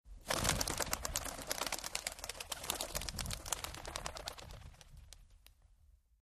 Pigeon startled, flying off